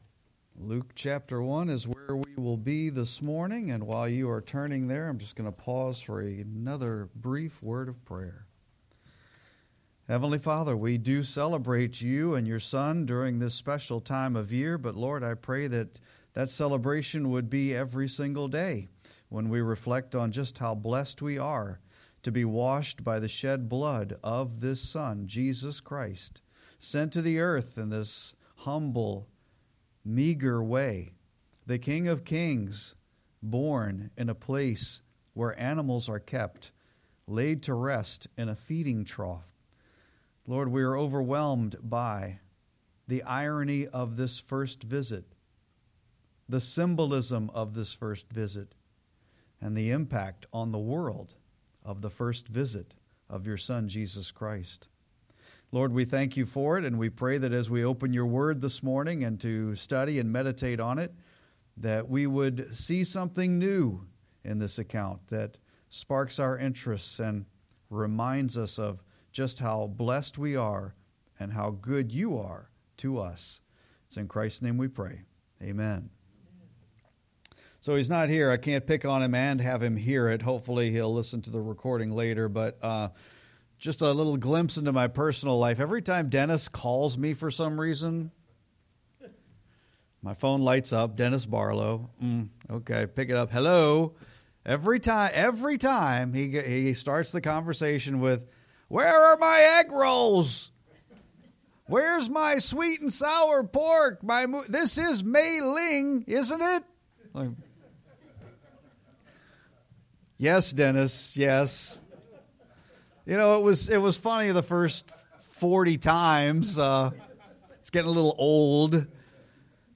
The Birth Announcement (Luke 1:26-56) – Freedom Baptist Church